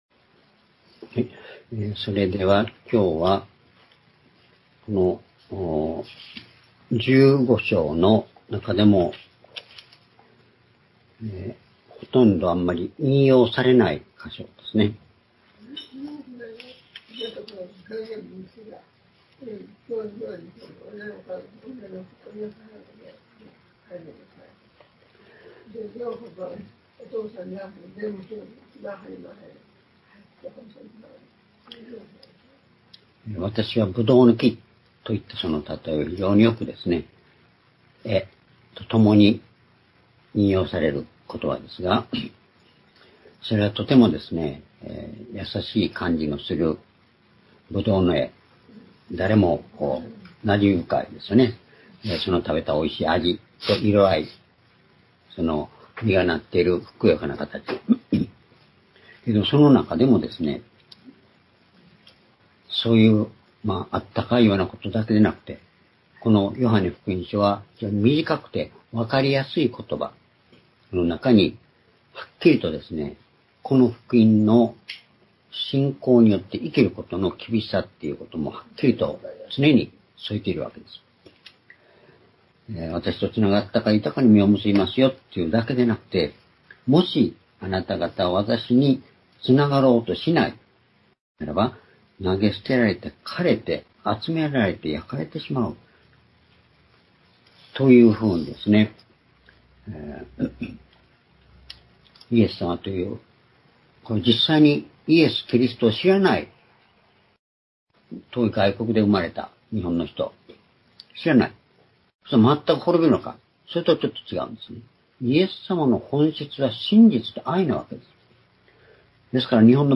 主日礼拝日時 2024年6月23日(主日) 聖書講話箇所 「苦しみ、闇の中の光」 ヨハネ15章18節～27節 ※視聴できない場合は をクリックしてください。